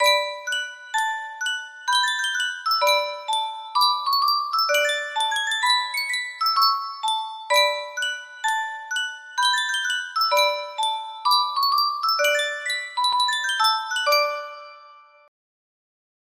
Sankyo Music Box - Toyland A8 music box melody
Full range 60